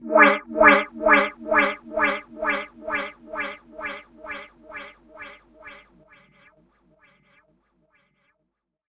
9 Rhodes FX 006.wav